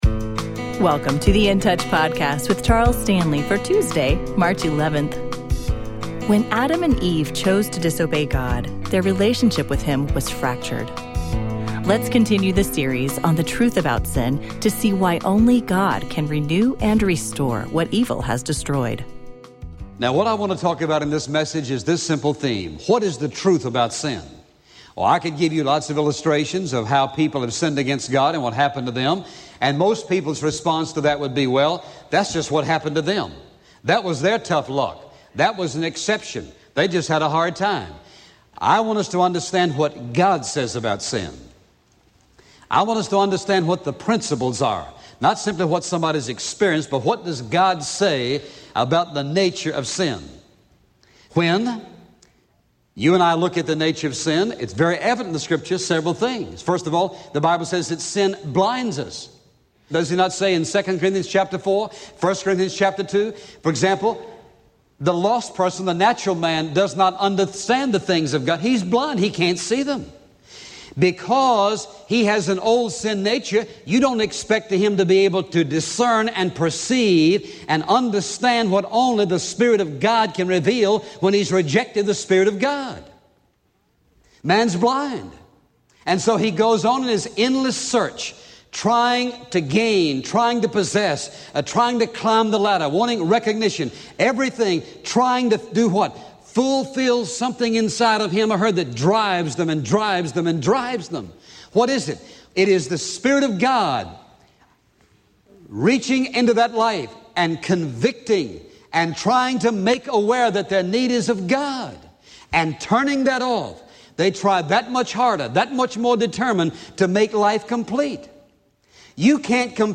Dr. Charles Stanley and In Touch Ministries’ daily radio program.